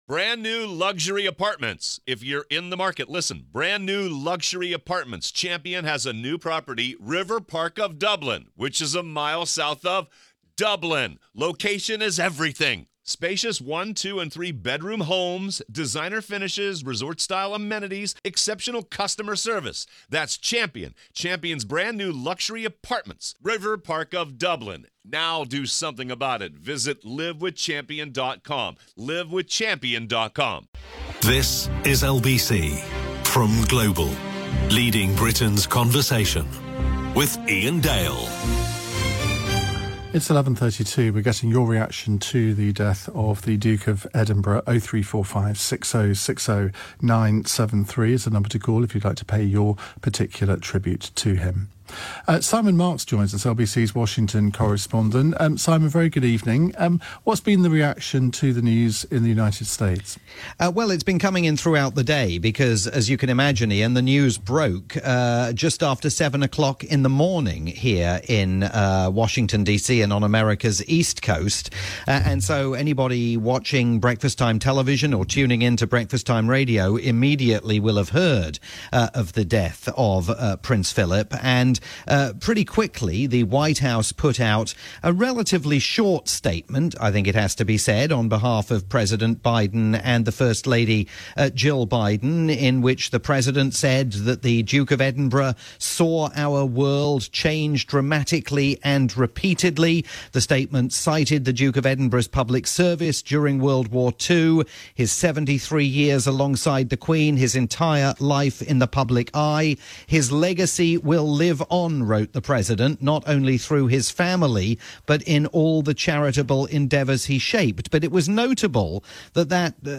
live update from the US